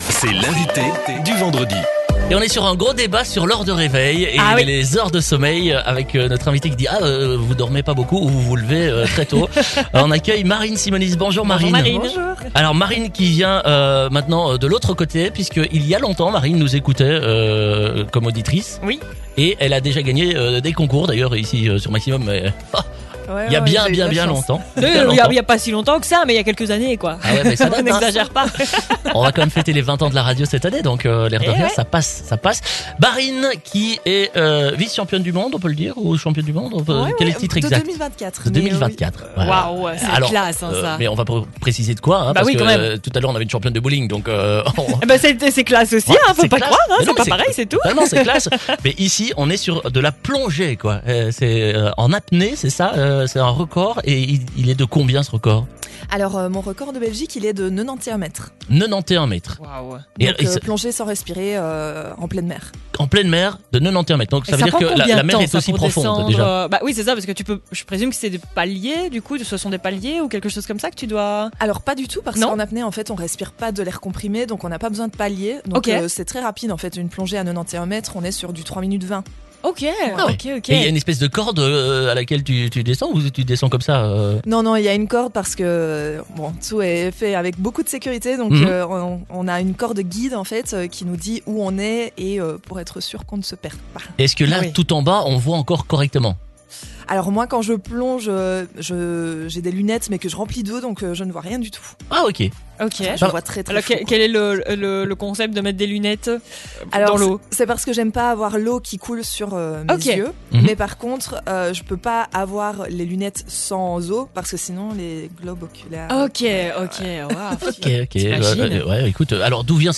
était notre invitée dans le Wake Up Liège !